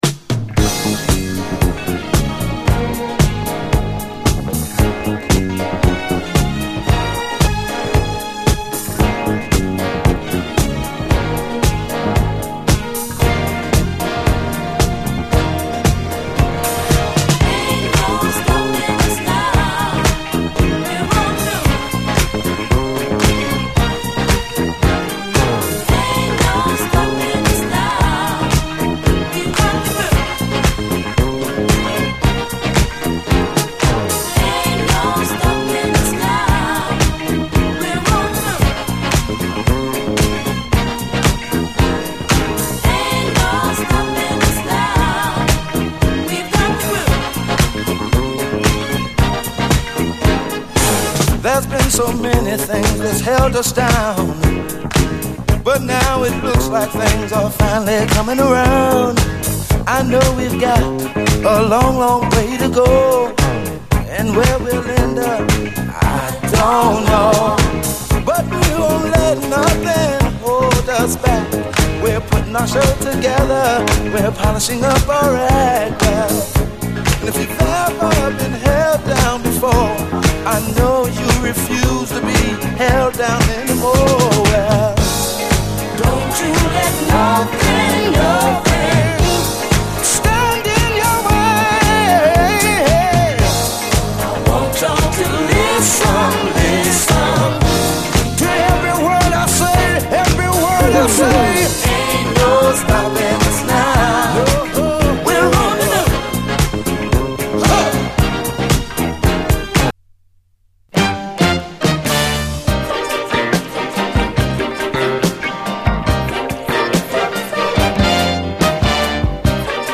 SOUL, 70's～ SOUL, DISCO